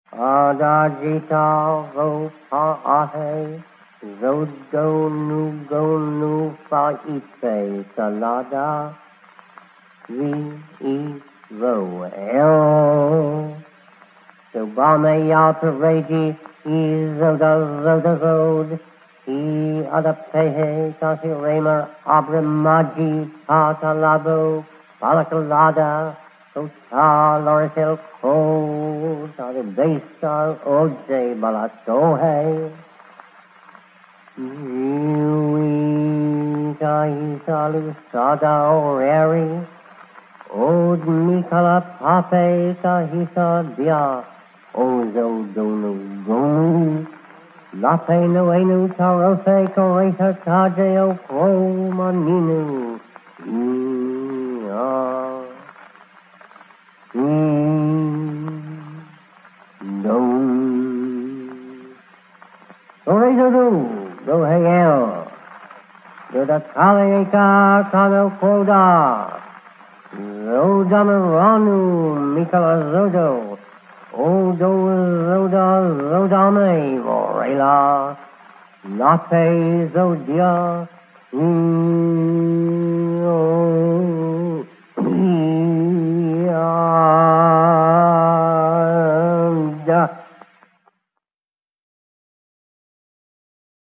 (d’après l’enregistrement sur cire de 1930)